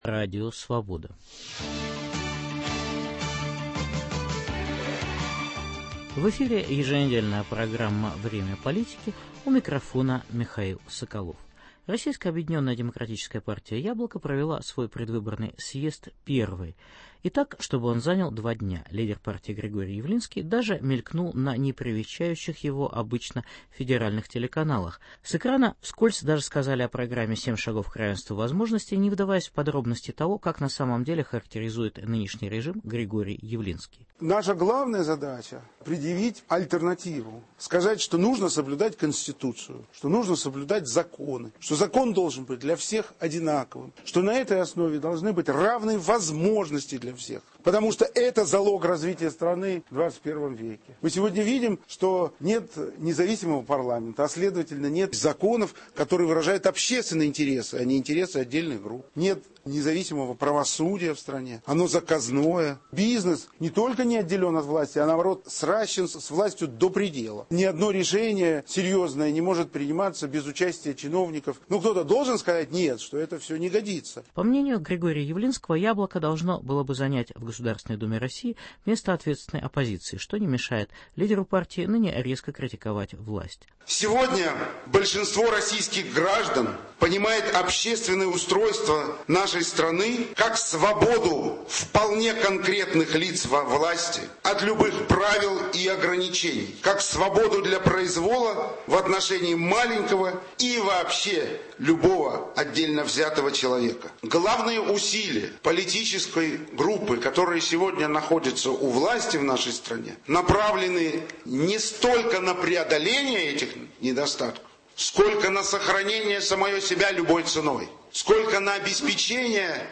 Репортажи со съездов партий "Яблоко" и "Зеленые". Предвыборная ситуация в Приморском и Краснодарском краях, Мордовии, Саратовской и Самарской областях.